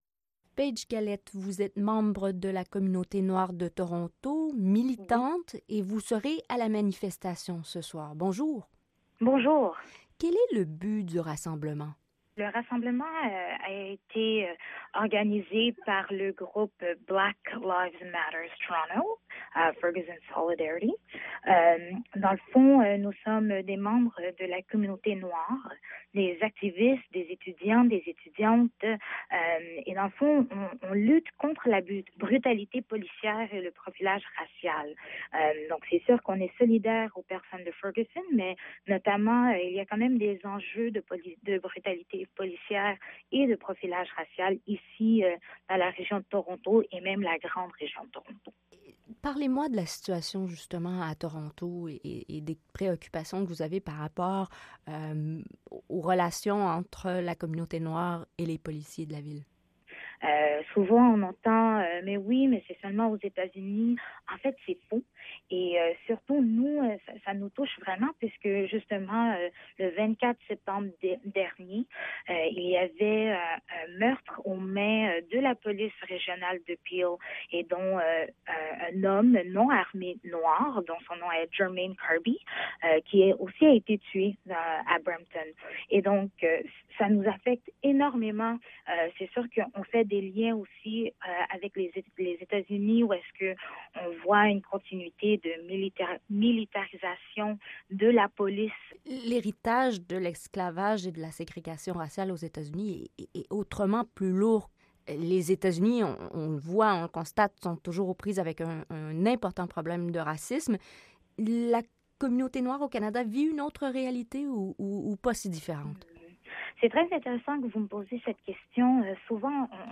« TAM-TAM CANADA », RADIO CANADA INTERNATIONAL